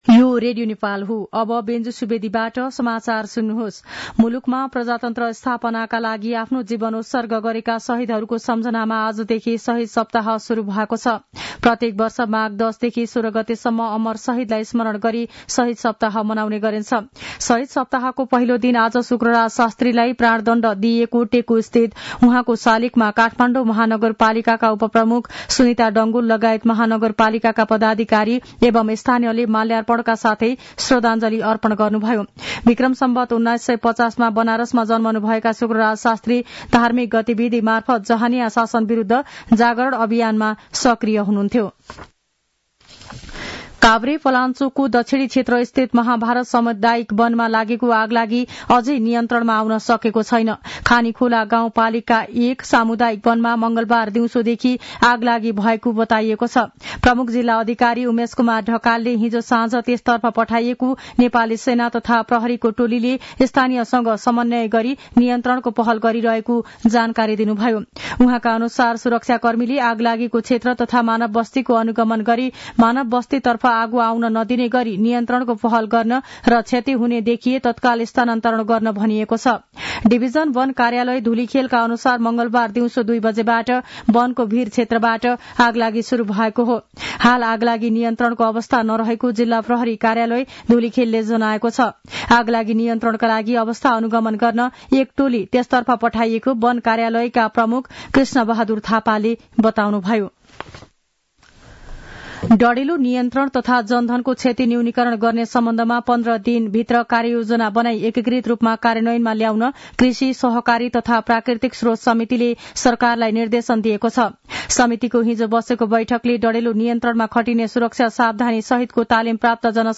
दिउँसो १ बजेको नेपाली समाचार : ११ माघ , २०८१
1-pm-news-3.mp3